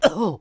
traf_damage6.wav